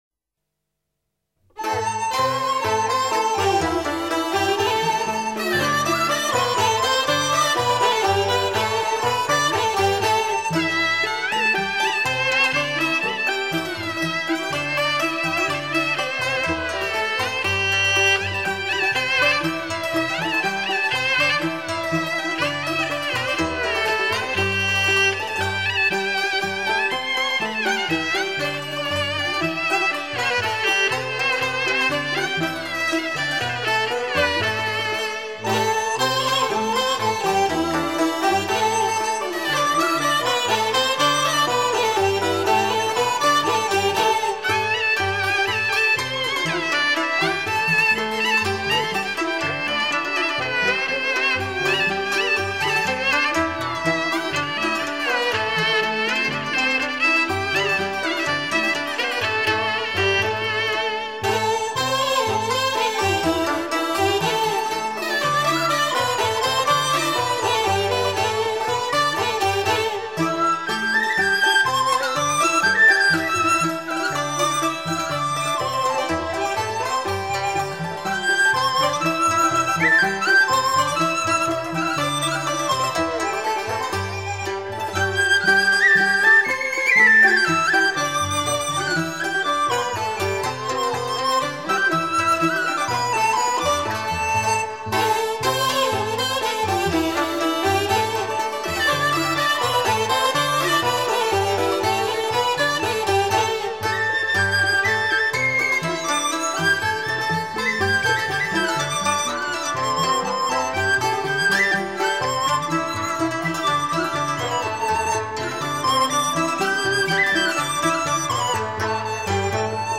广东音乐是中国民乐的重要分支之一。
曲调流畅、活跃，节奏清晰。